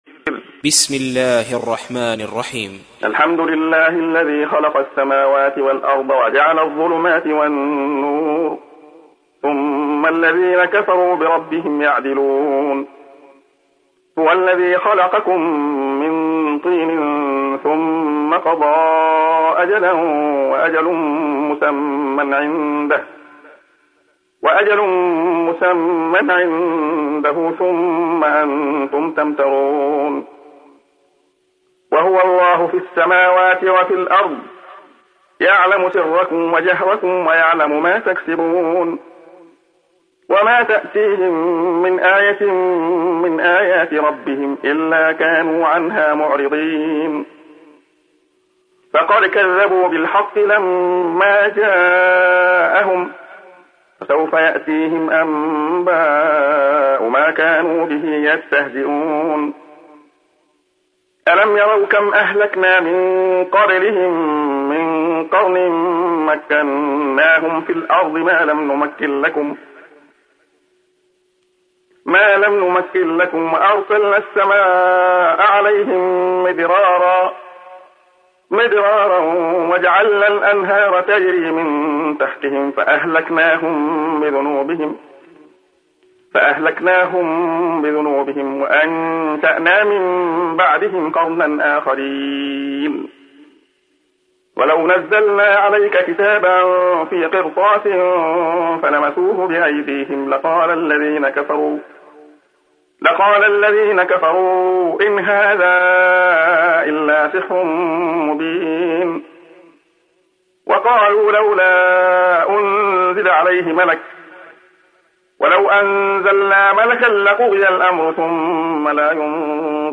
تحميل : 6. سورة الأنعام / القارئ عبد الله خياط / القرآن الكريم / موقع يا حسين